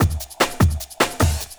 50 LOOP01 -L.wav